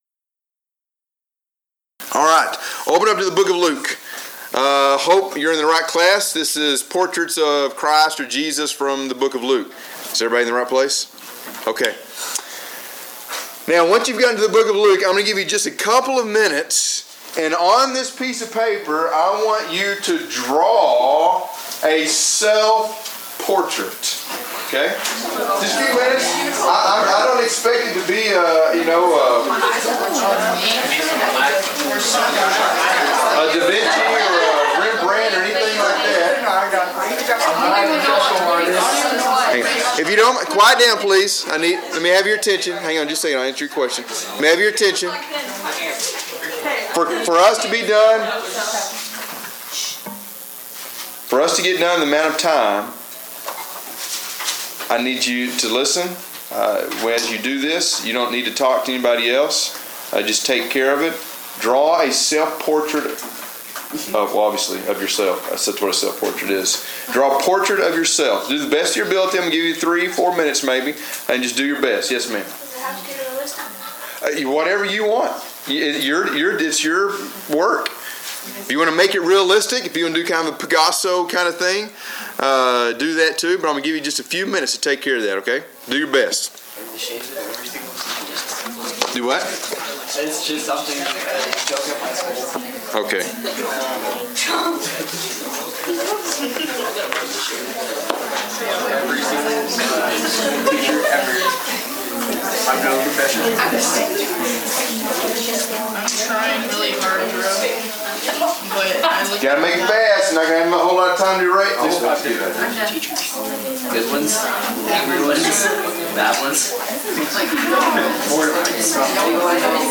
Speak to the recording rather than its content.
Event: Discipleship U 2016